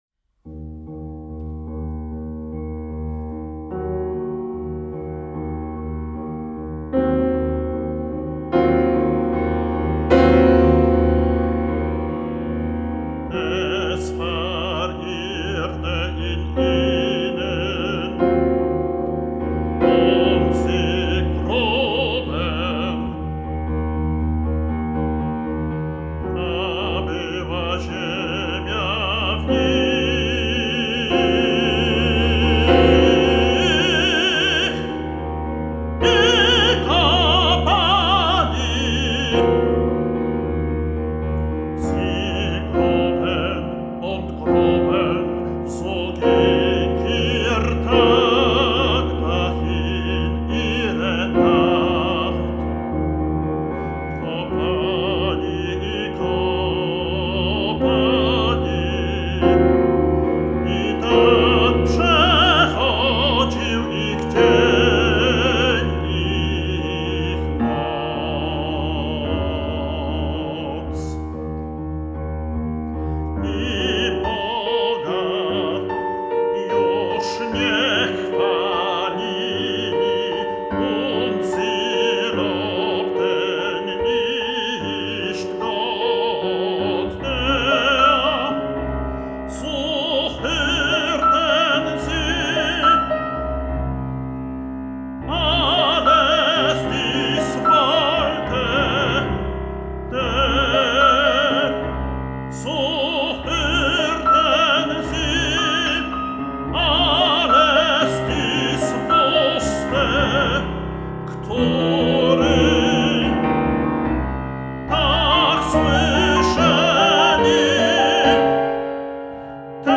tenor
fortepian
sonoryzm, onomatopeja muzyczna